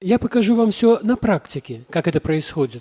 мужской голос
громкие
голосовые